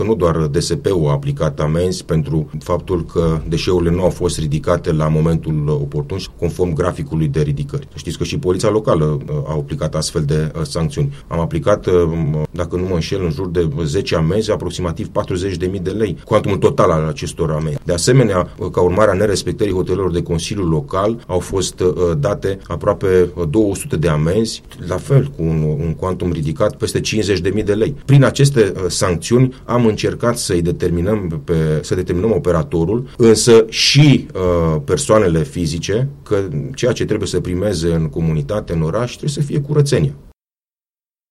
Nu doar DSP Alba a aplicat amenzi operatorului de salubritate din Alba Iulia ci și Poliția Locală, a declarat, la Unirea FM, viceprimarul Emil Popescu.